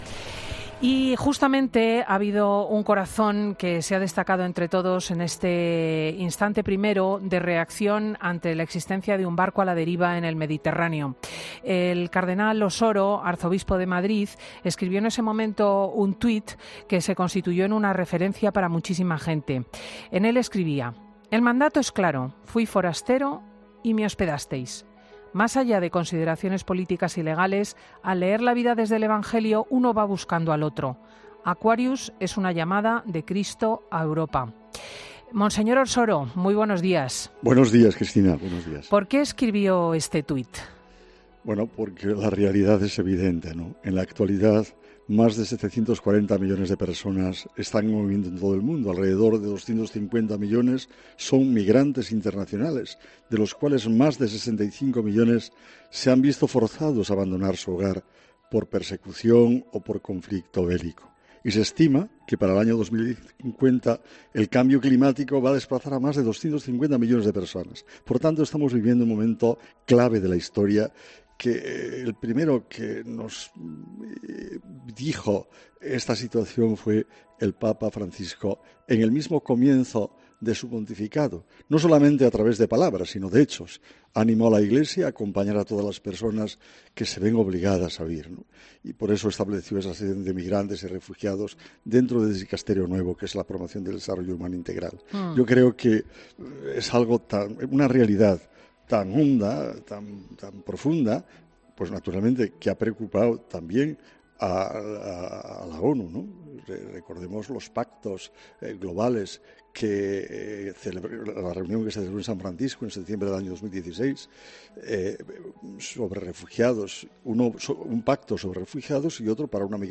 El cardenal y arzobispo de Madrid, Carlos Osoro en 'Fin de Semana'